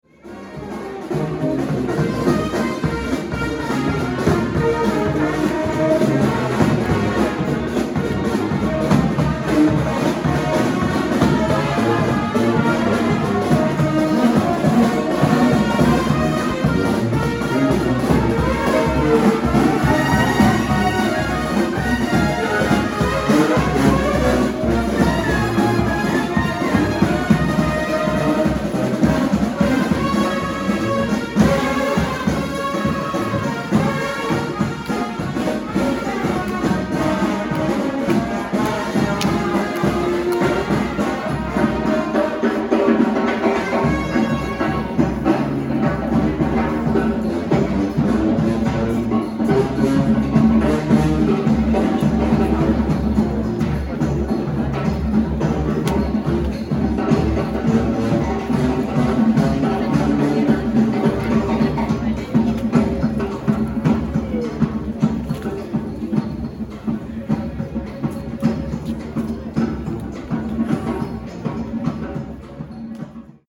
Бродячие музыканты на улицах (играют и ходят)